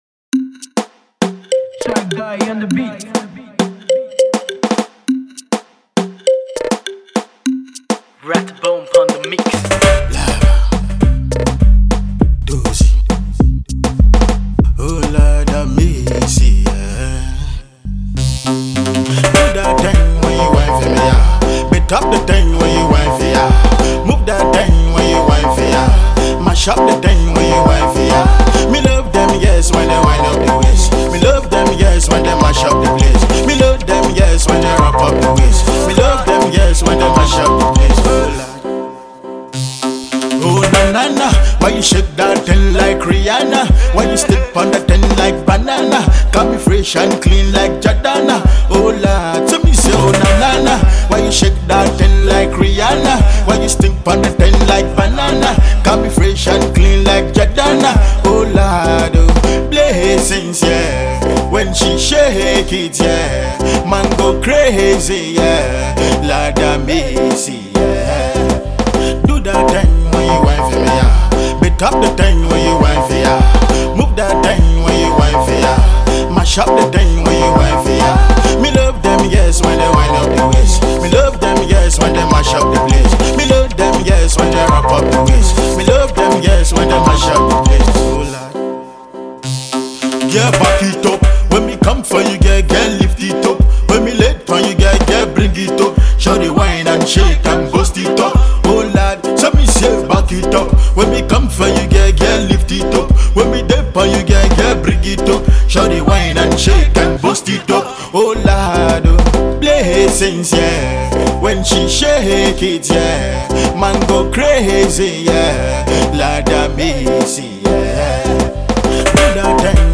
Dance Hall Reggae
Musicien de reggae dance-hall super star du Nigeria